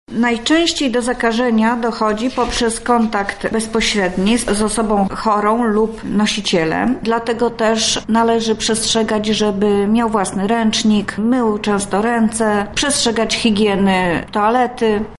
Jak zmniejszyć ryzyko tłumaczy Anna Strzyż, zastępca wojewódzkiego Inspektora Sanitarnego w Lublinie: